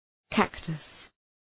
Προφορά
{‘kæktəs}